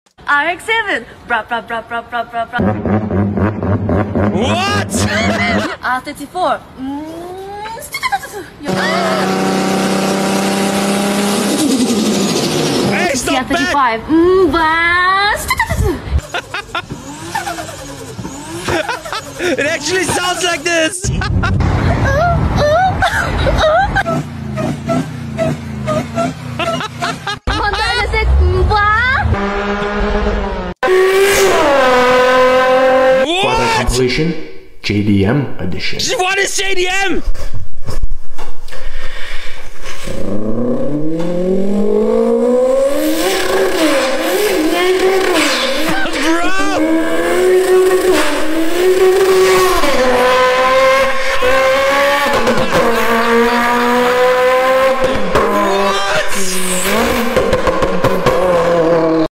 ♡Amateur Car Sounds Vs Pro Sound Effects Free Download